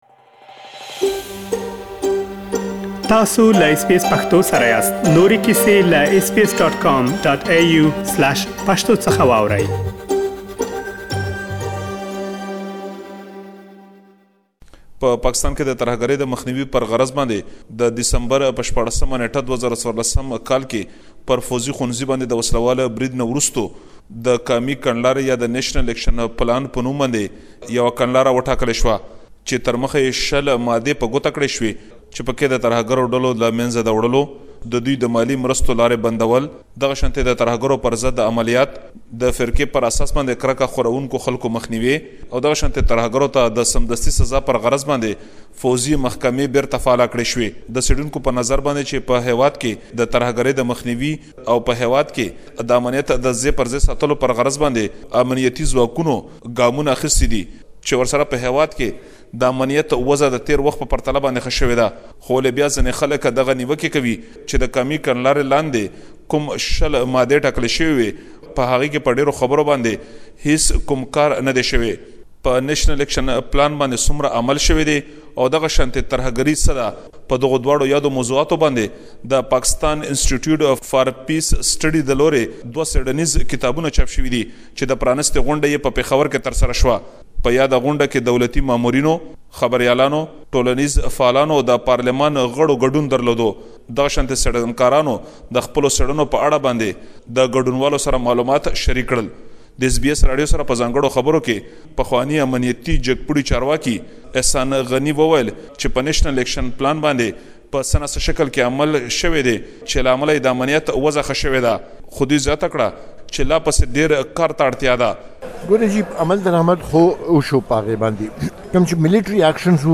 ياد پلان جوړيدو څخه ۷ کاله تير شول او داچې څه شوي دې ټولو لا روښانه کولو لپار مو له امنيتي کار کوونکو او پوهانو سره خبرې کړي.